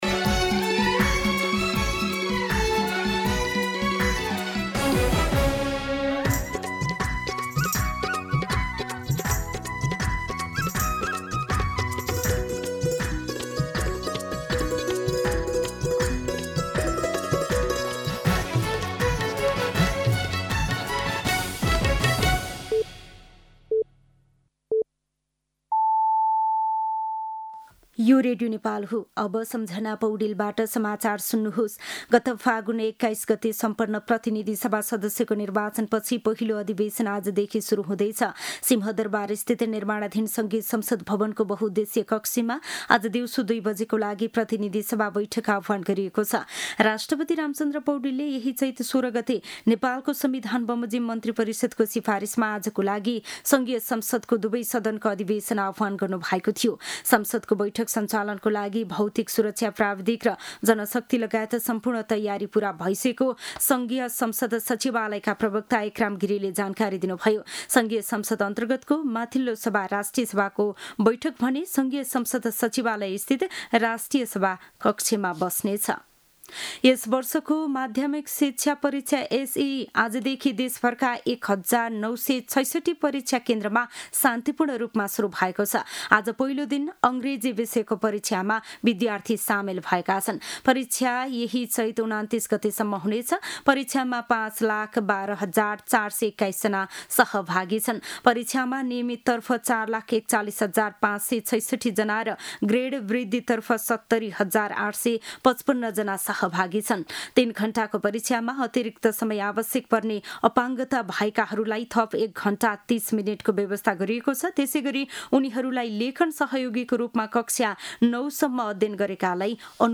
मध्यान्ह १२ बजेको नेपाली समाचार : १९ चैत , २०८२